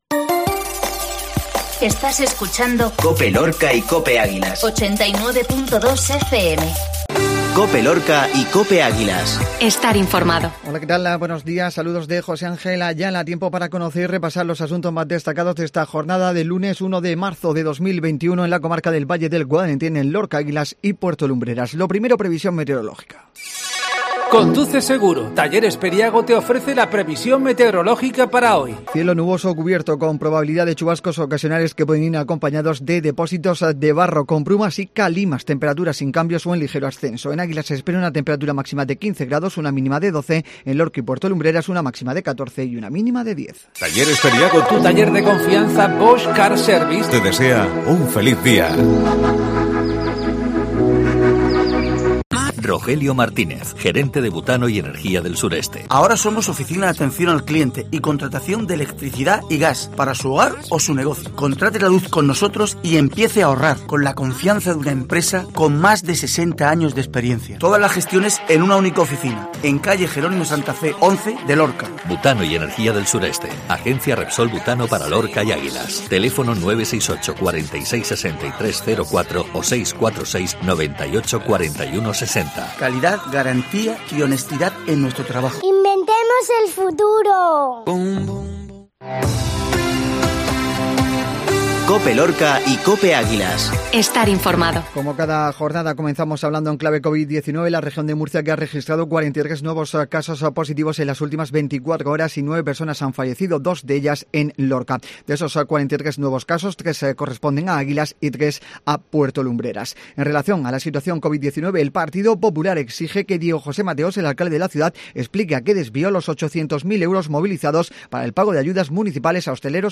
INFORMATIVO MATINAL LUNES